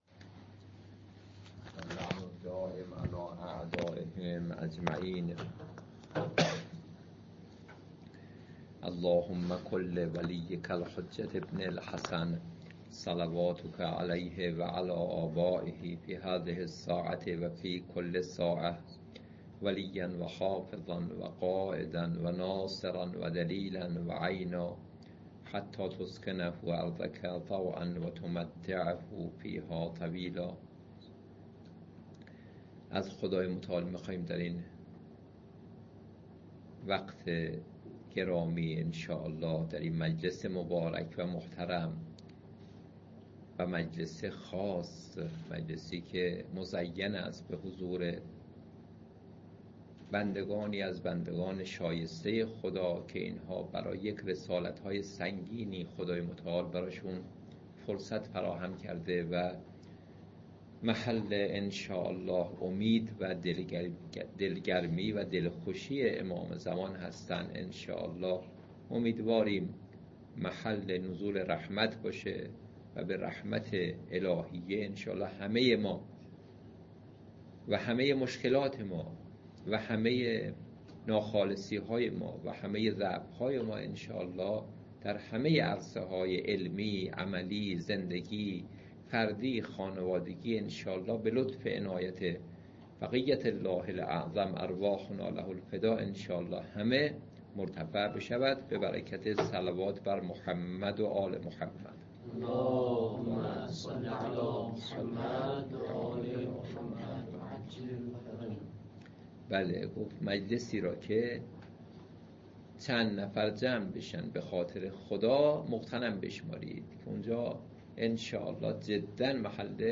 درس الاخلاق
حیات طیبه سلوک مؤمنانه در زندگی مشترک محفل خانواده های محترم طلاب در مدرسه عالی امام حسین علیه السلام جلسه اول: 📌📃 برای مطالعه متن و محتوای کلاس اینجا را کلیک کنید.